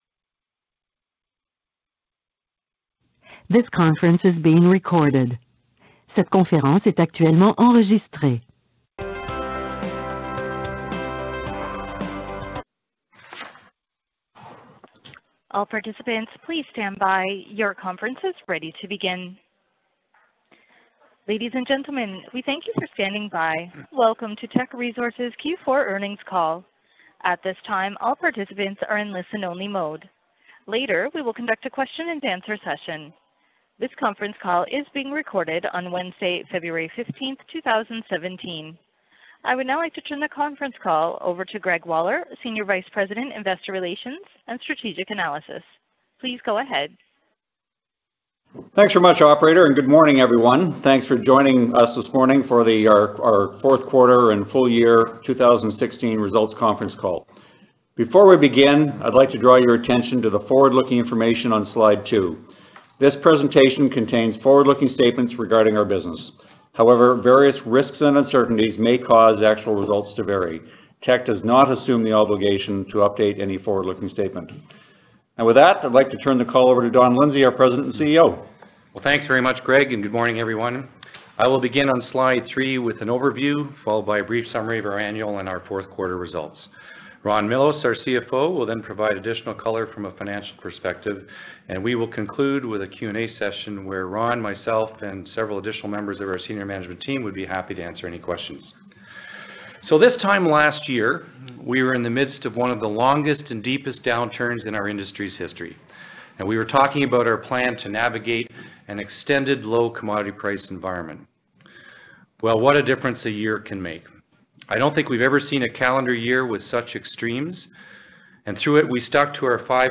Q4.Teck_Earnings_Call.mp3